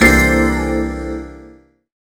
Vibez Hit-C3.wav